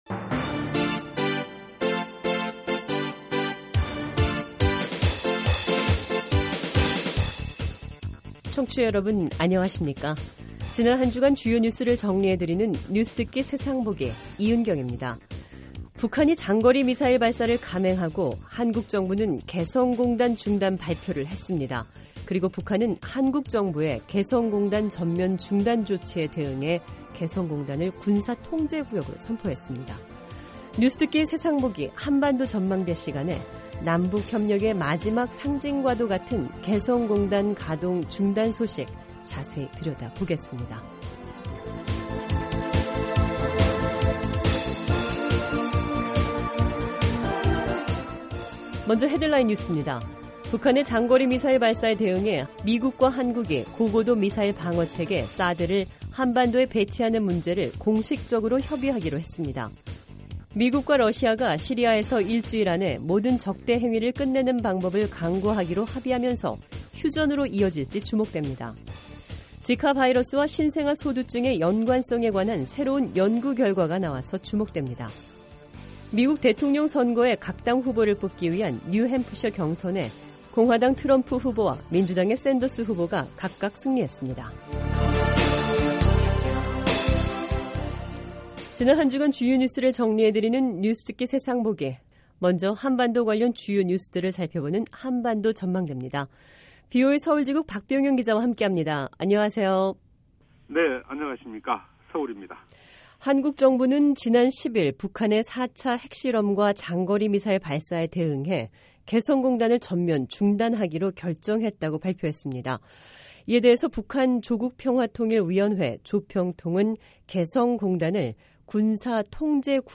지난 한주간 주요 뉴스를 정리해 드리는 뉴스듣기 세상보기 입니다. 북한이 장거리 미사일 발사를 감행하고 한국 정부는 개성 공단 중단 발표를 했습니다. 미국과 러시아가 시리아에서 1주일 안에 모든 적대 행위를 끝내는 방법을 강구하기로 합의하면서, 휴전으로 이어질 지 주목됩니다.